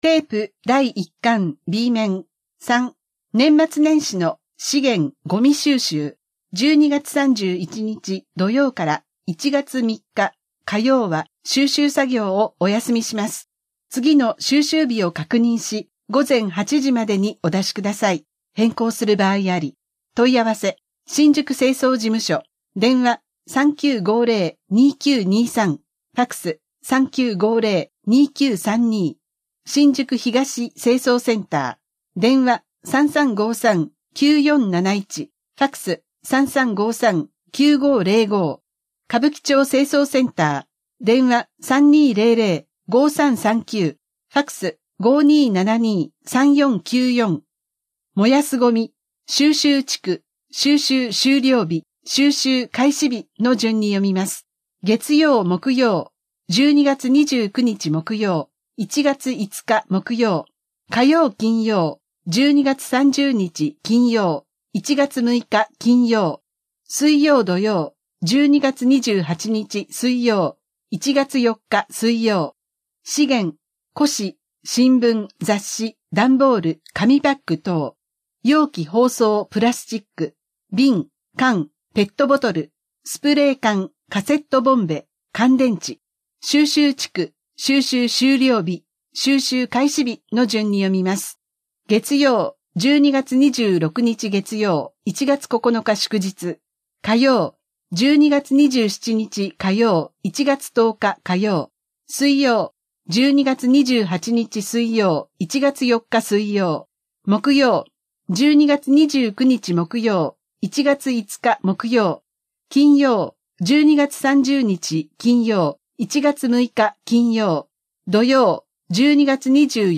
声の広報（目次） ［MP3形式：5.61MB］（新規ウィンドウ表示） (1)1巻の表（12月3日~9日は障害者週間、ノロウイルス食中毒・感染症、1月の保健センターの教室・相談 ほか） ［MP3形式：19.2MB］（新規ウィンドウ表示） (2)1巻の裏（年末年始の資源・ごみ収集、福祉タクシー利用券を追加発送します、もしかして・・・・・・うつ病？